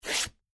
鞋与地面的摩擦-YS070525.mp3
通用动作/01人物/01移动状态/鞋与地面的摩擦-YS070525.mp3
• 声道 立體聲 (2ch)